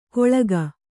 ♪ koḷaga